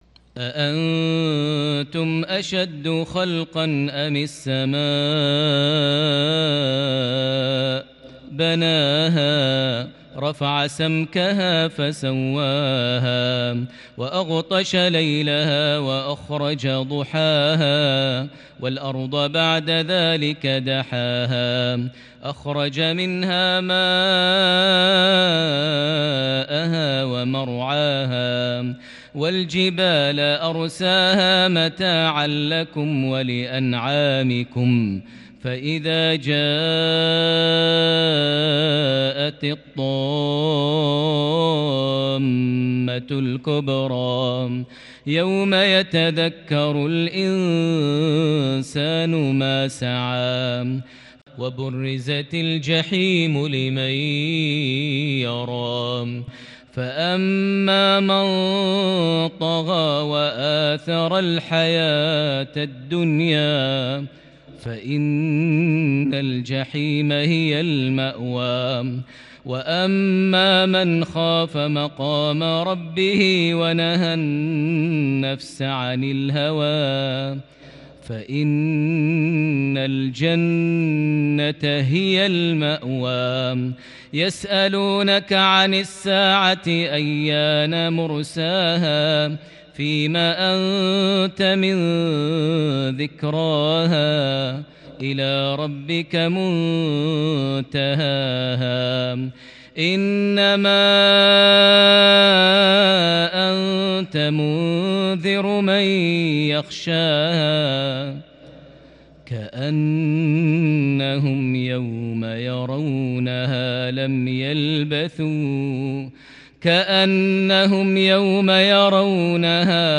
صلاة المغرب خواتيم سورة النازعات - سورة الشمس 17 رجب 1442هـ | mghrip 1-3-2021 prayer fromSurah An-Naziat + Surah Ash-Shams > 1442 🕋 > الفروض - تلاوات الحرمين